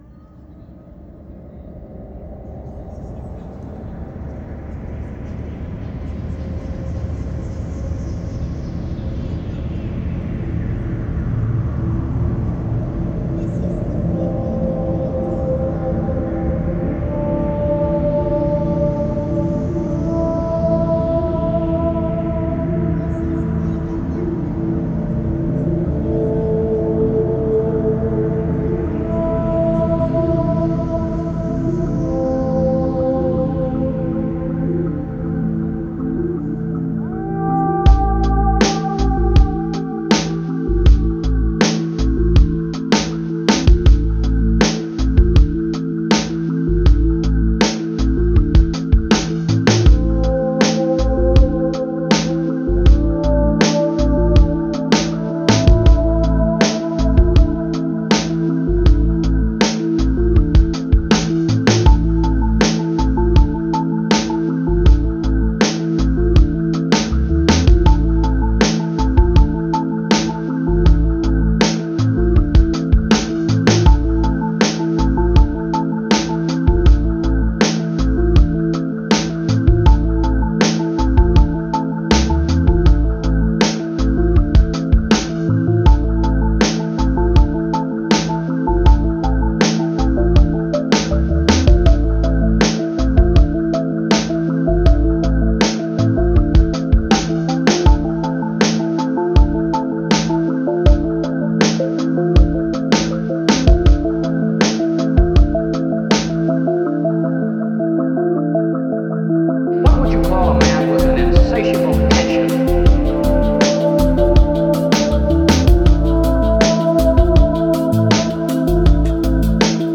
Genre: Ambient, IDM, Chillout.